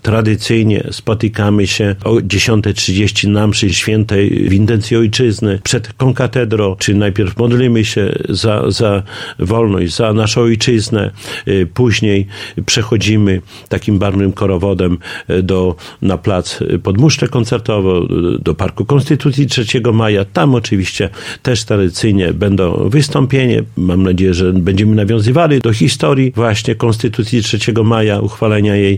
Na uroczystości zaprasza Czesław Renkiewicz, prezydent Suwałk.